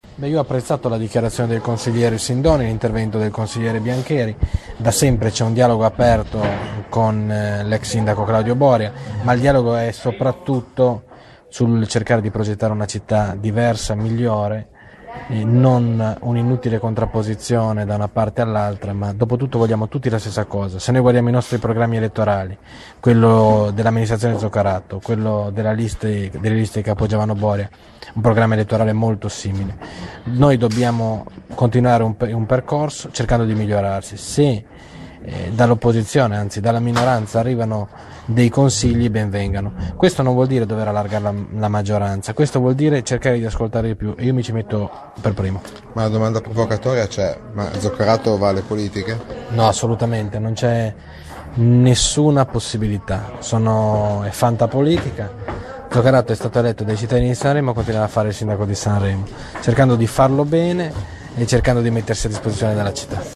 Alla fine del Consiglio il sindaco ha così commentato l'apertura di alcuni esponenti dell'opposizione (l'intervista audio cliccando